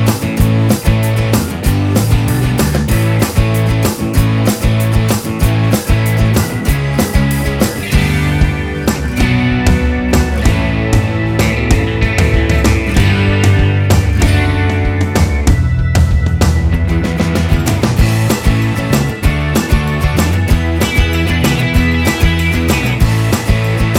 no Backing Vocals T.V. Themes 3:03 Buy £1.50